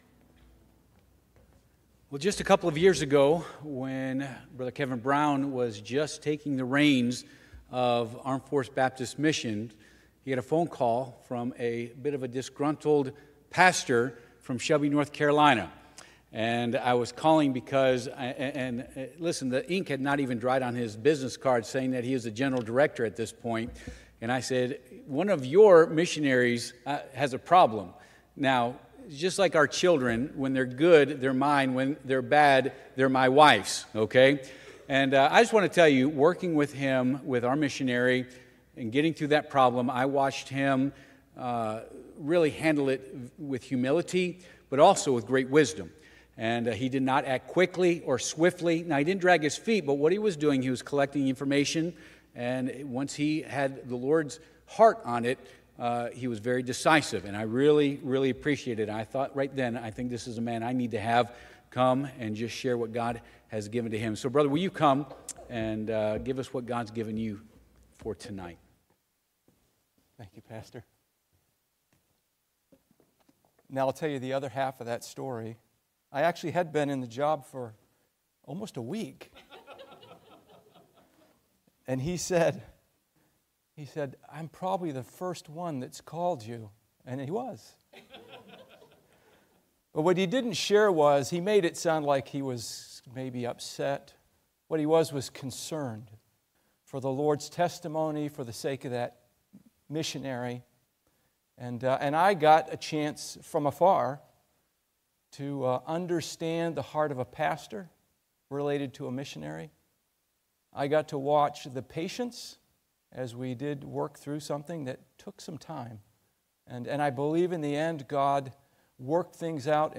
Passage: Acts 10:1-8 Service Type: Midweek Service